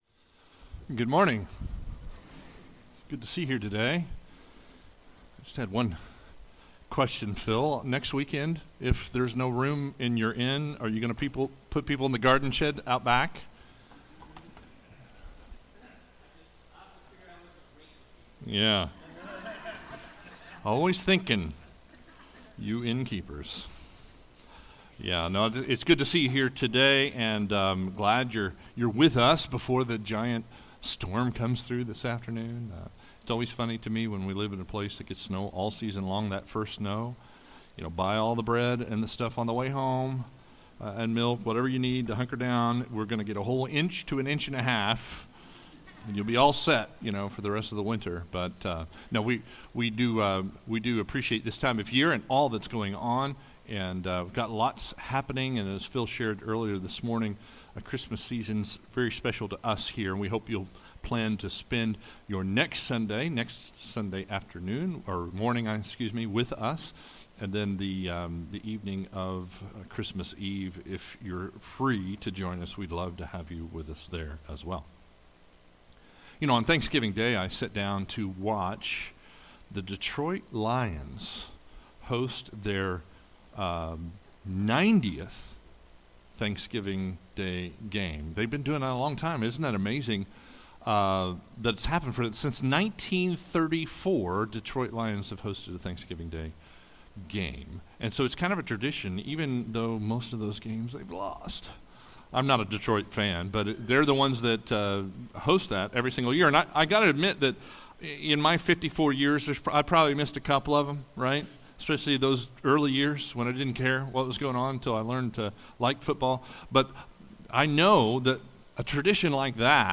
Sermons | Watermarke Church